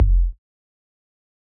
Kick (beibs in the trap).wav